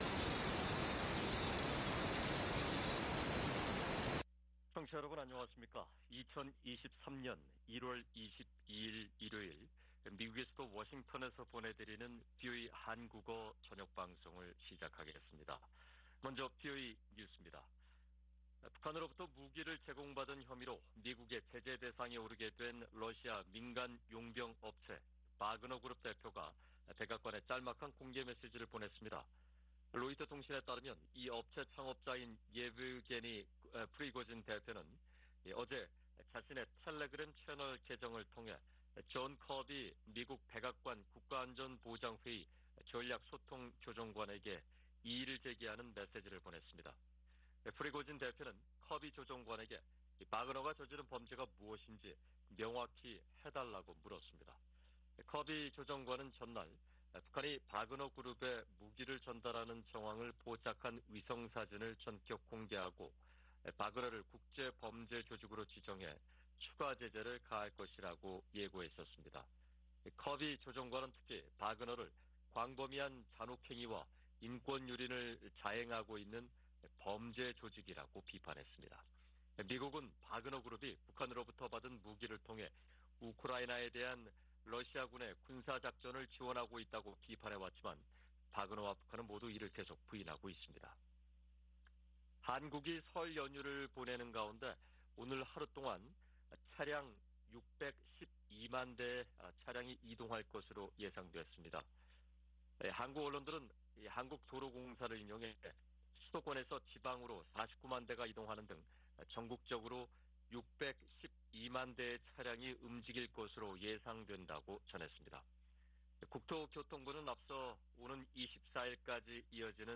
VOA 한국어 방송의 일요일 오후 프로그램 1부입니다. 한반도 시간 오후 8:00 부터 9:00 까지 방송됩니다.